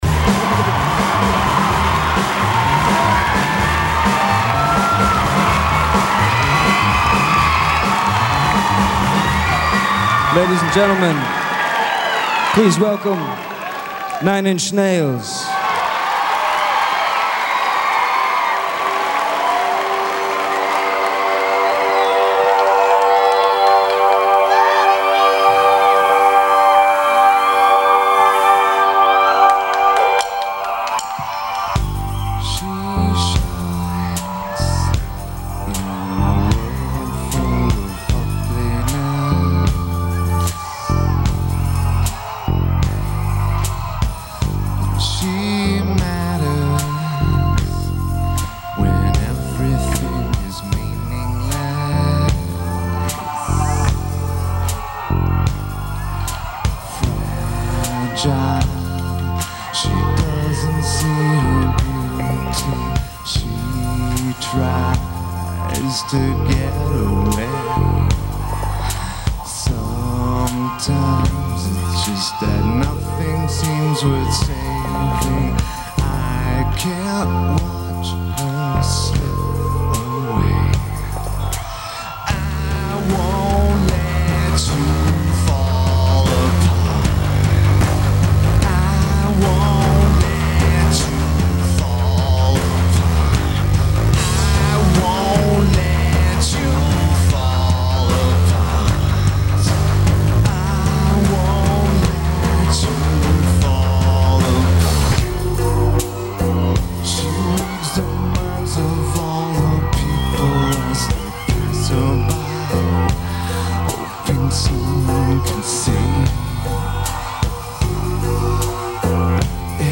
Radio City Music Hall
Keyboards/Programming
Bass/Guitar
Drums
Vocals/Guitar/Keyboards
Lineage: Audio - PRO (Soundboard)